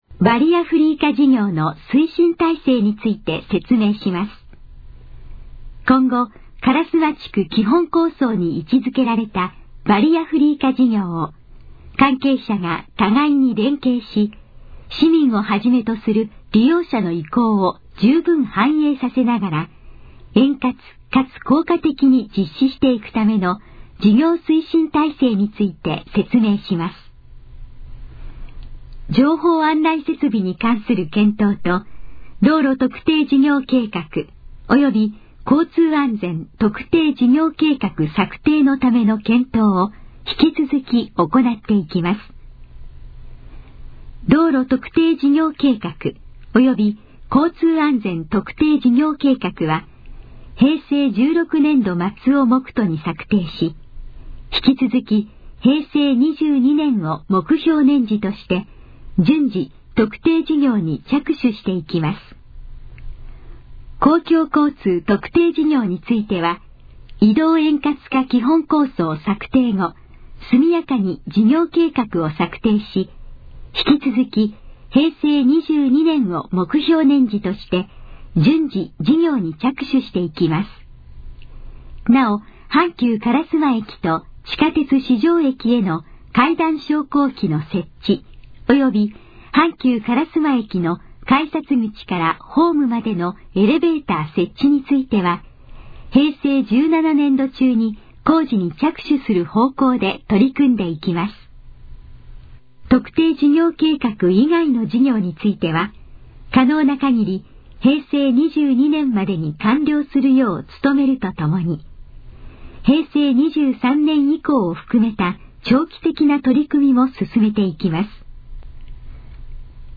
このページの要約を音声で読み上げます。
ナレーション再生 約344KB